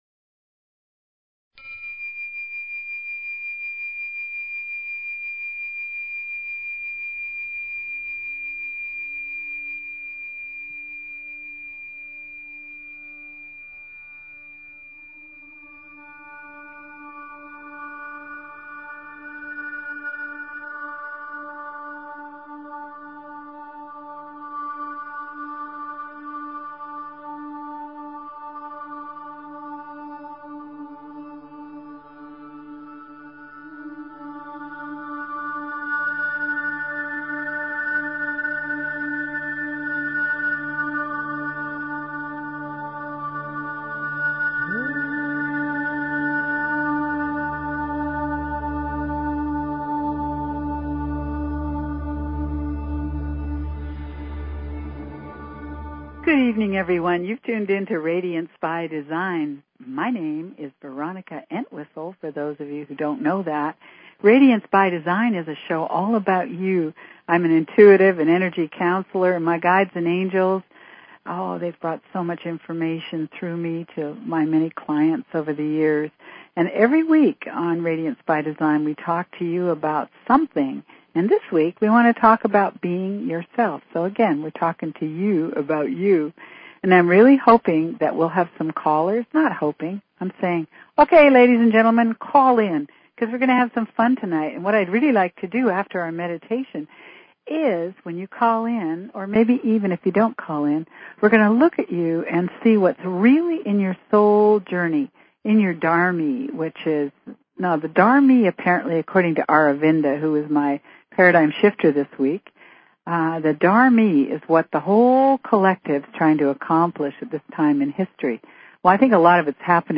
Talk Show Episode, Audio Podcast, Radiance_by_Design and Courtesy of BBS Radio on , show guests , about , categorized as
Learn meditations, energy structures and have fun! Radiance is a call in show so call in about your life, your questions, the trickery that you find in your daily routine.